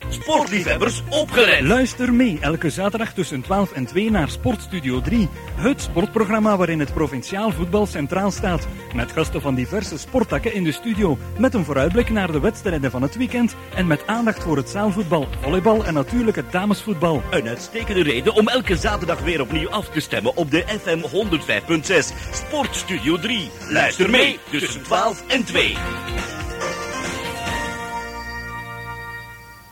Opmerkingen: Reclamespot voor Sportstudio 3 op zaterdag die werd geprogrammeerd ter promotie van het programma